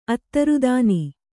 ♪ attarudāni